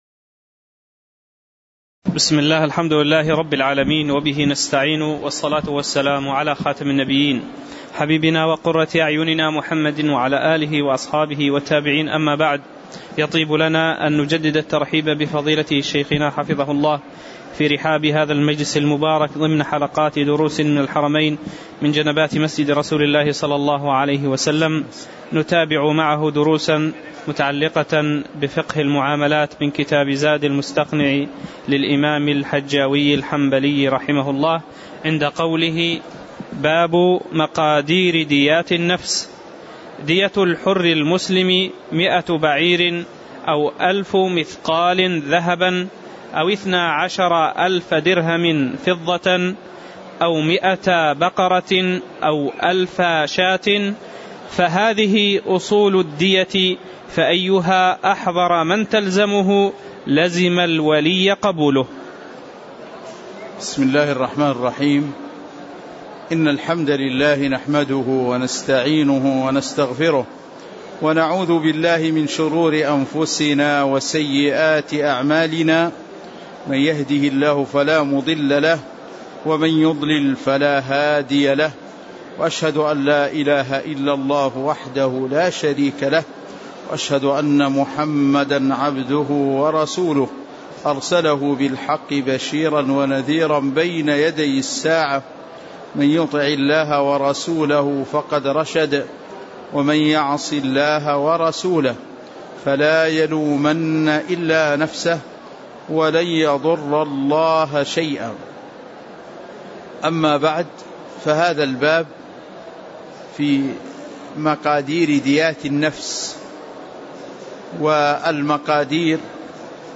تاريخ النشر ١٤ ربيع الأول ١٤٣٨ هـ المكان: المسجد النبوي الشيخ